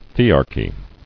[the·ar·chy]